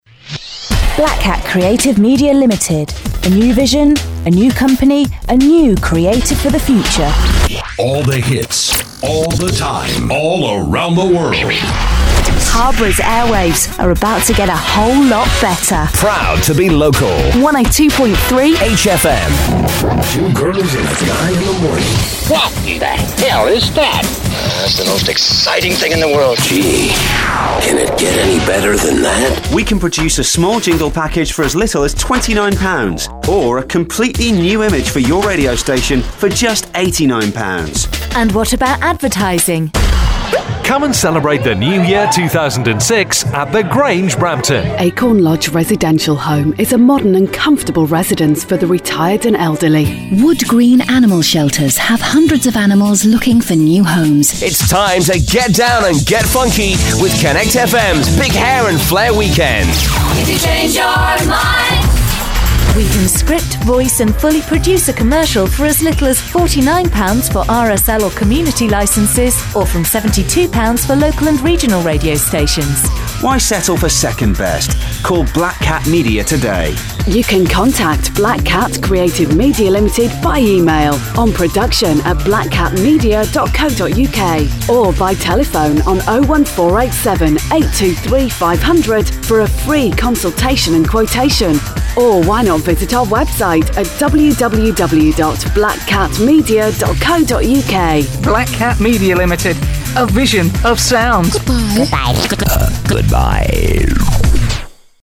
Check out our voice talent for yourselves.
90sec BCM Showreel June 07.mp3